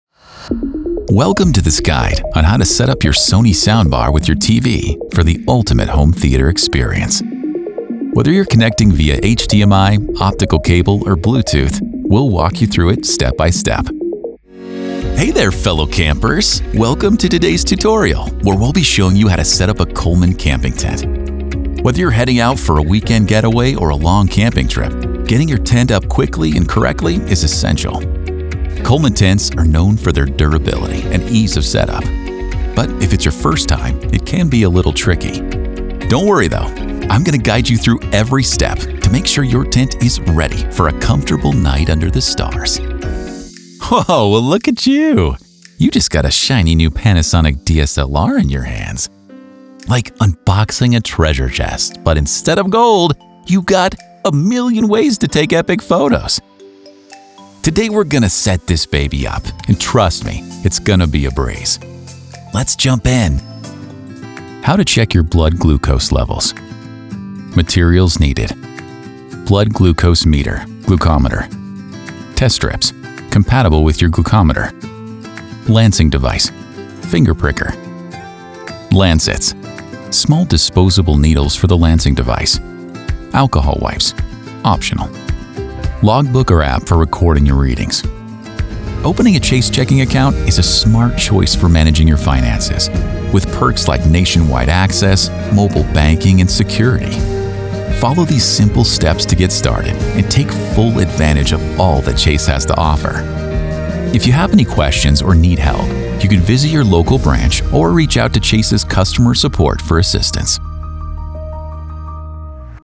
His voice has been described as trustworthy, friendly, conversational, warm, rich, and versatile.
Adult, Mature Adult
Location: Denver, CO, USA Languages: english Accents: standard us | natural Voice Filters: VOICEOVER GENRE COMMERCIAL 💸 NARRATION 😎 NARRATION FILTERS explainer video phone message